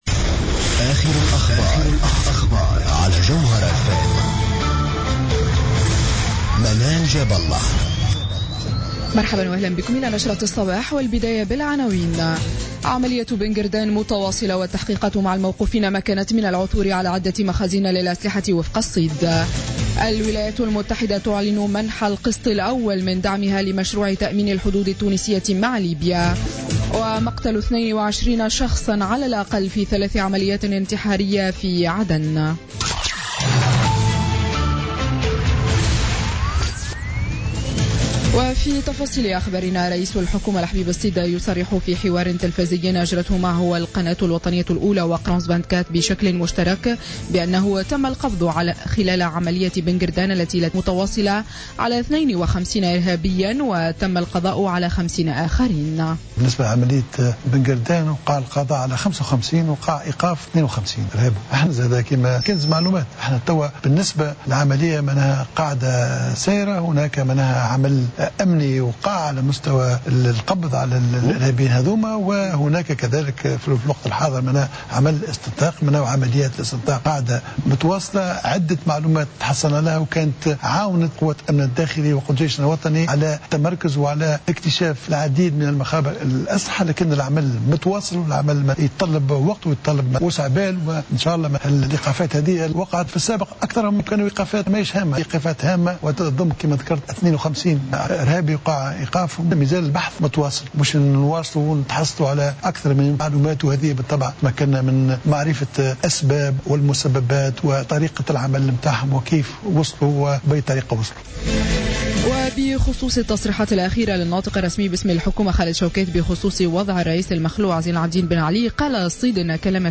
Journal Info 07h00 du samedi 26 Mars 2016